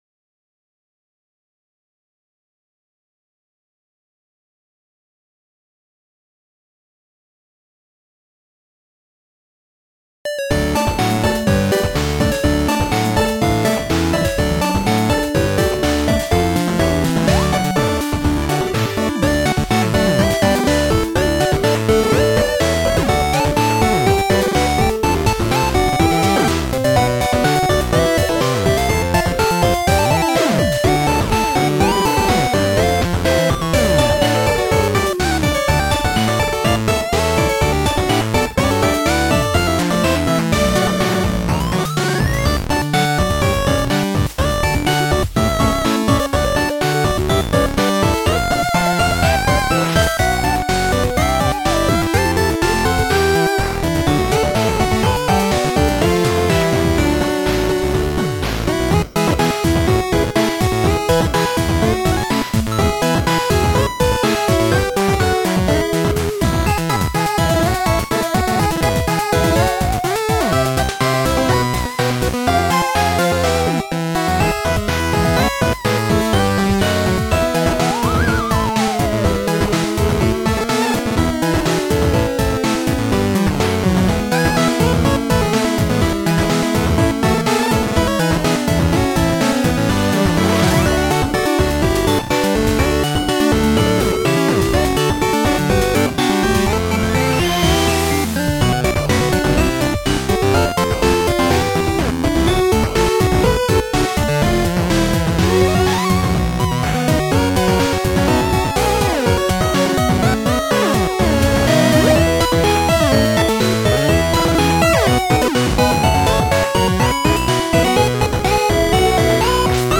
This song uses the VRC6 expansion audio